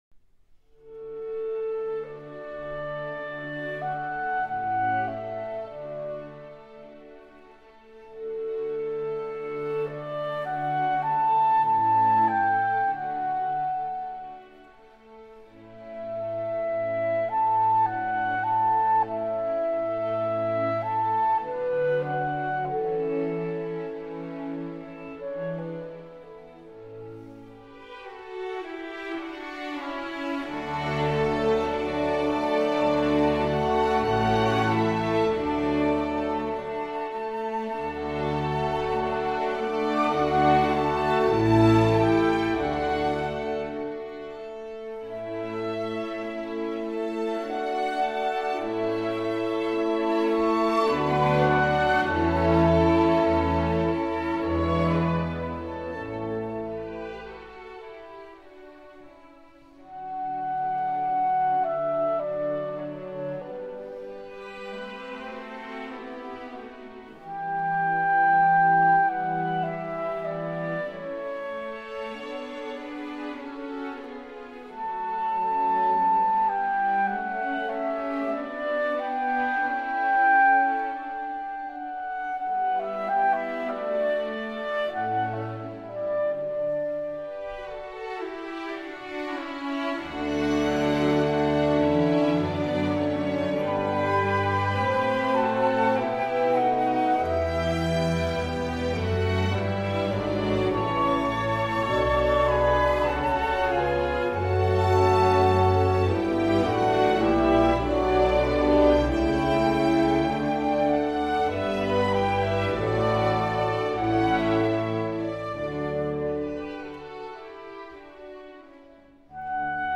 Klarinetconcert K 622 in A Major II. Adagio - Wolfgang Amadeus Mozart
Mozart - Adagio from Clarinet Concerto in A Major.mp3